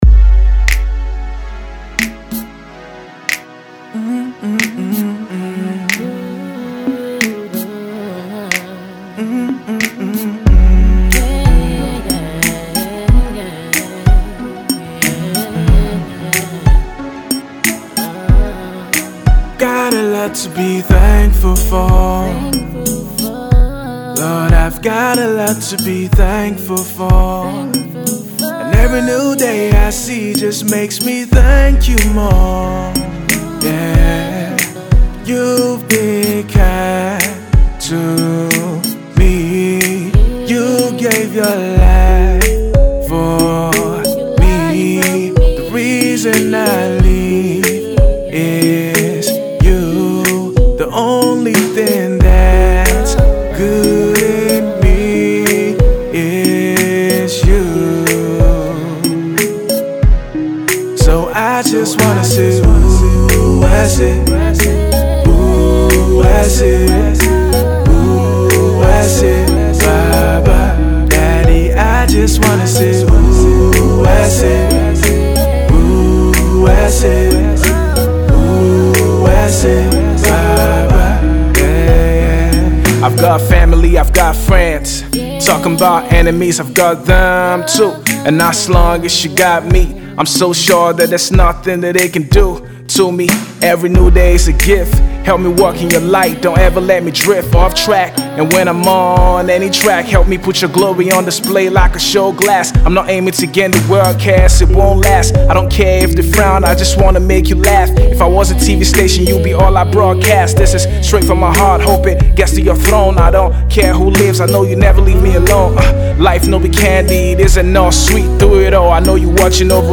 Afro-hiphop